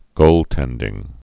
(gōltĕndĭng)